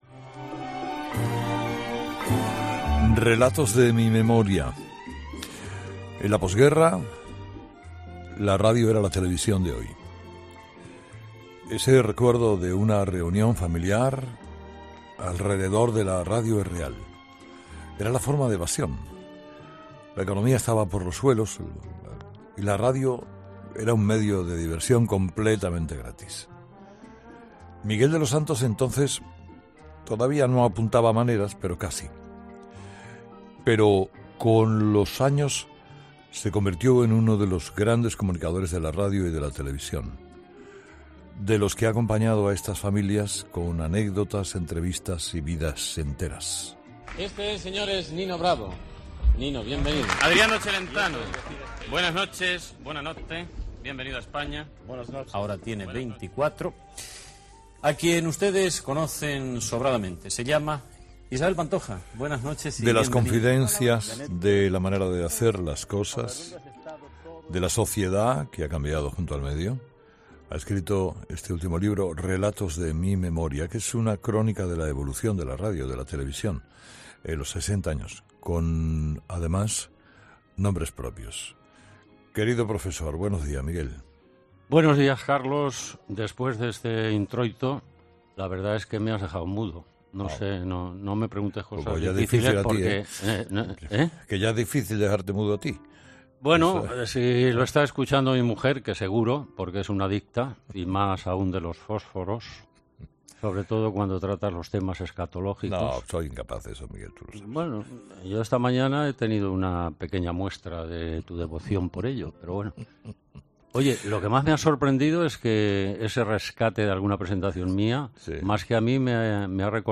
Entrevista
Info-entreteniment